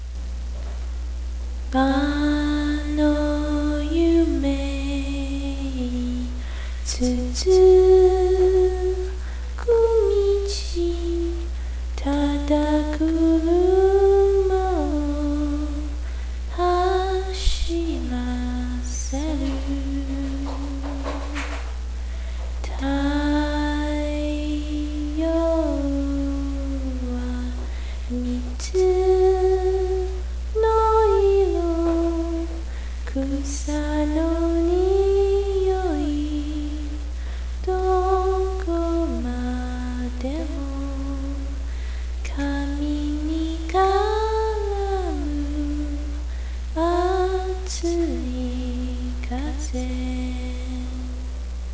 - - v o c a l    .w a v - -
J-pop